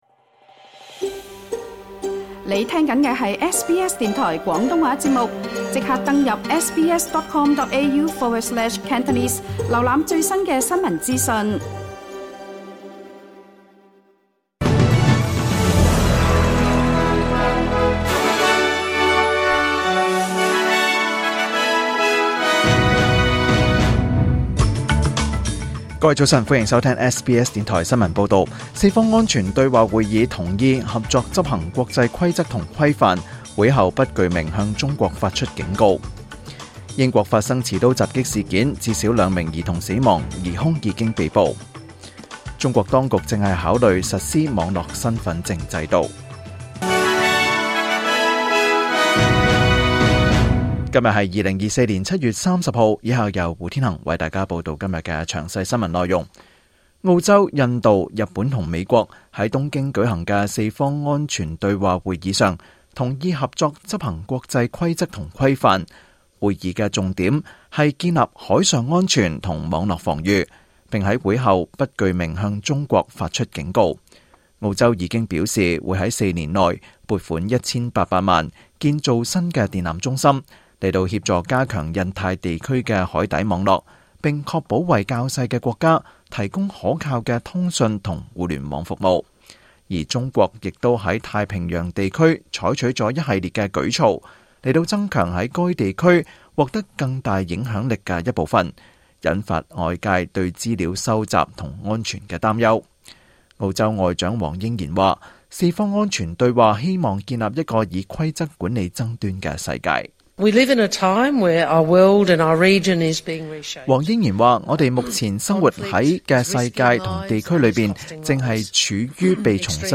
2024年7月30日SBS廣東話節目詳盡早晨新聞報道。